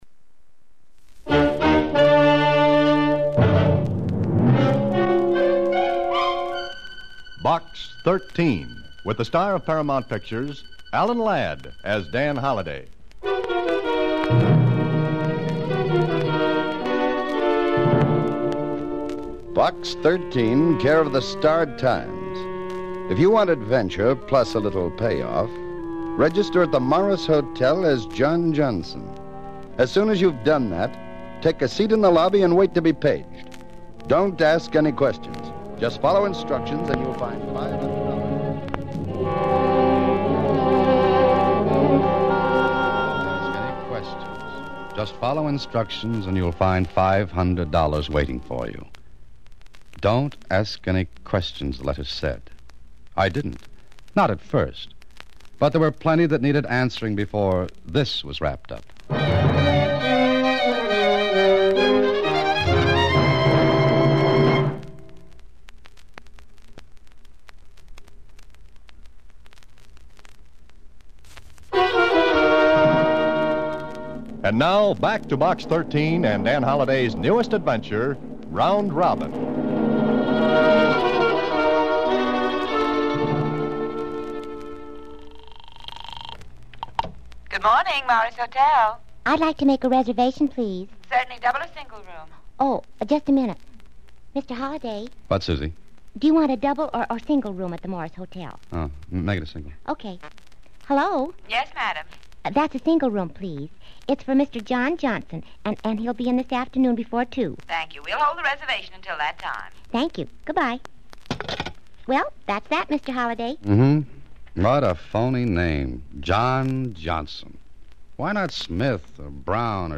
Episode 52 not only wraps up the series' long-running narrative but also encapsulates the innovative spirit of radio storytelling, where sound, suspense, and character interplay coalesce to create an unforgettable auditory